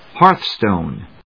音節héarth・stòne